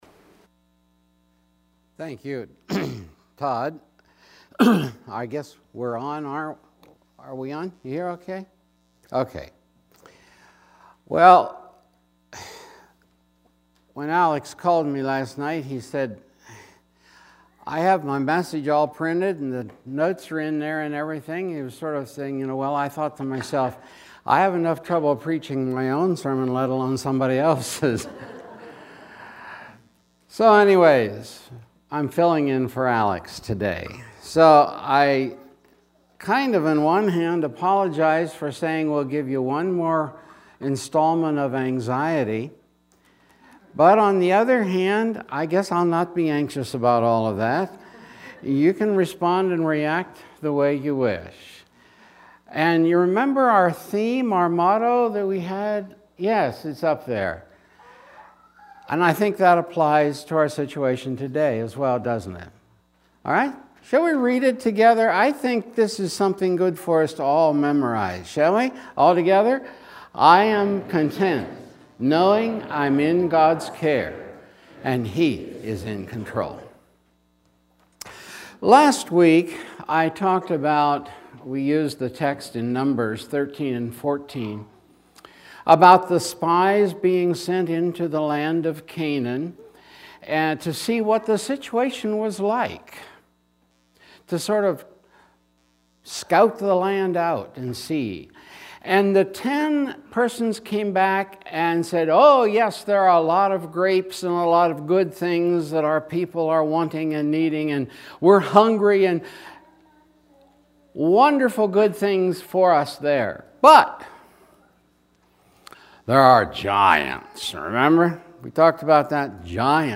Service 9:30 am Worship